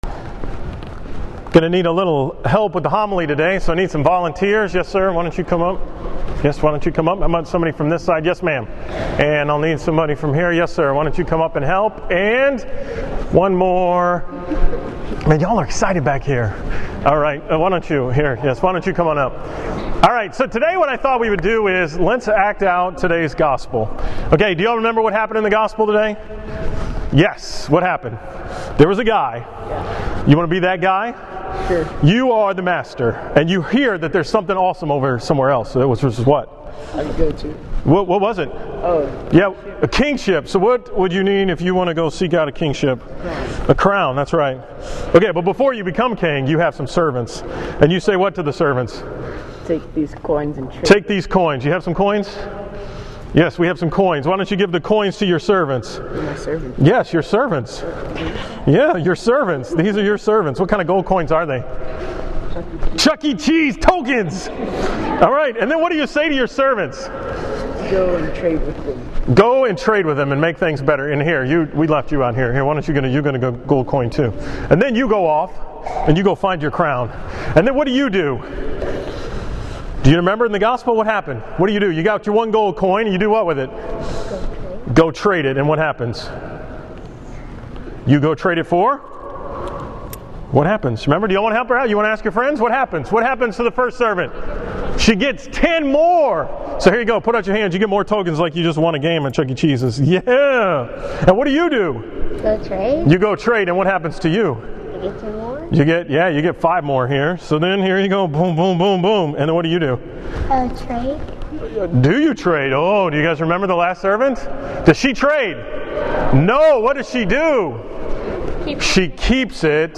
From the school Mass at St. Ambrose on November 18, 2015